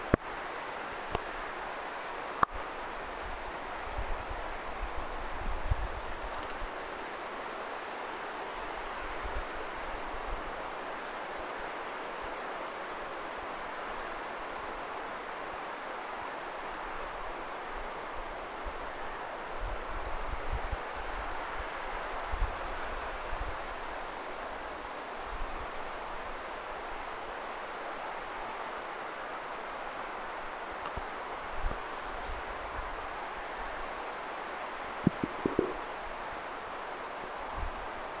ilmeisesti nuoria keltanokkarastaita
ilmeisesti_nuoria_keltanokkarastaita.mp3